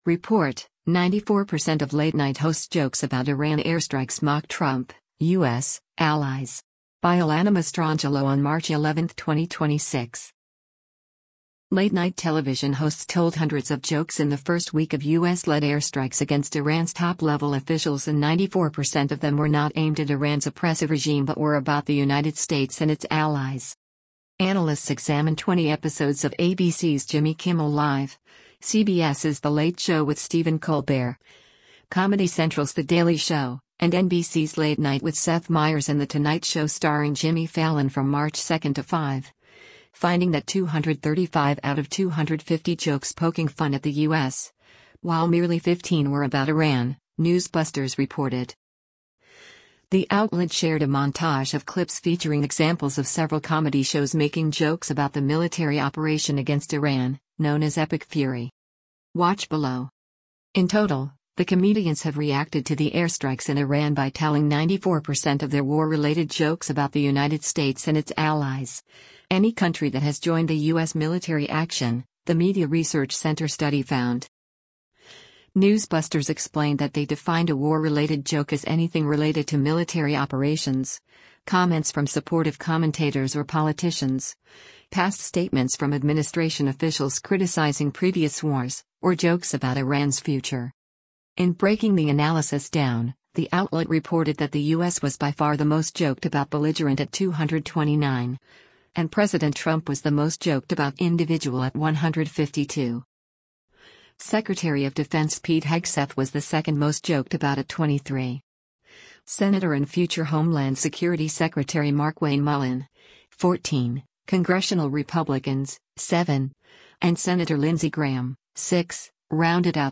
The outlet shared a montage of clips featuring examples of several comedy shows making jokes about the military operation against Iran, known as Epic Fury.